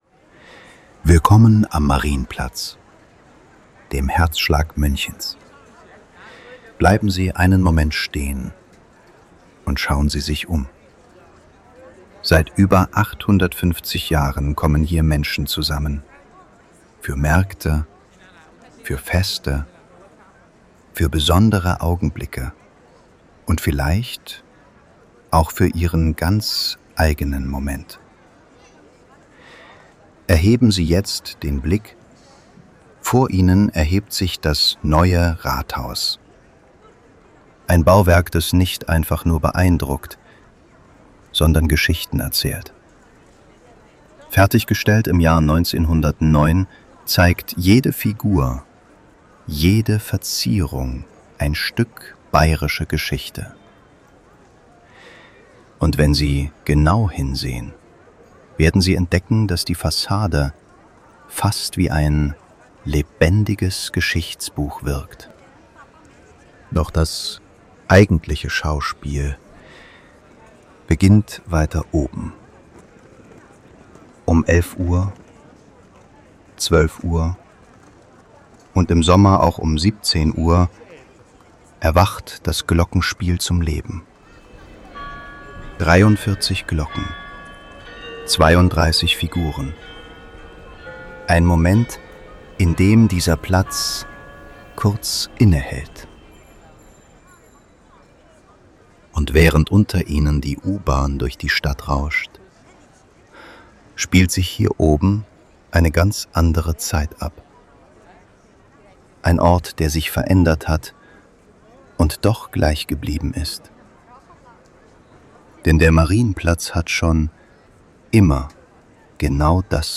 Audiofeature · Flagship
Ein akustischer Spaziergang über den Marienplatz in München – von seiner Geschichte bis zum Neuen Rathaus. Der Höhepunkt ist das legendäre Glockenspiel – erst im Detail beschrieben, dann im Original zu hören.
Eigenes Konzept und Script als cineastisches Audiofeature mit klarer dramaturgischer Struktur, gezielten Pausen, Tempowechseln und originalem Glockenspiel als akustischem Höhepunkt.